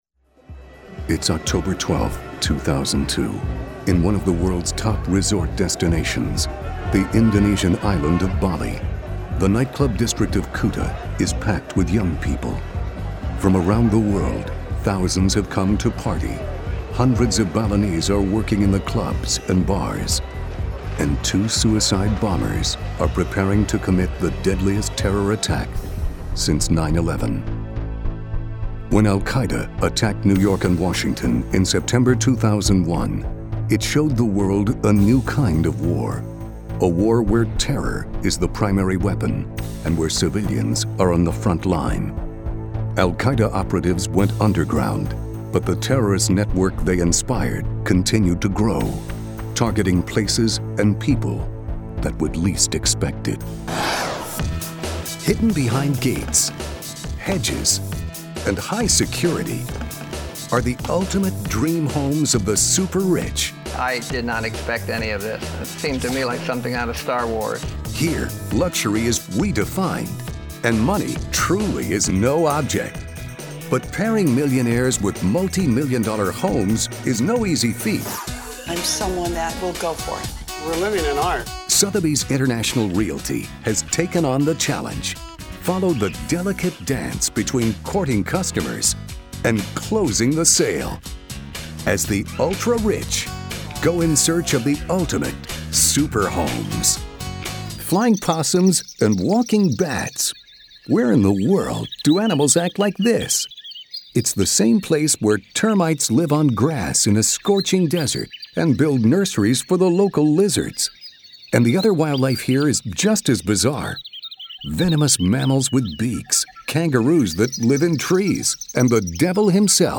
Male VOs
Listen/Download – Narration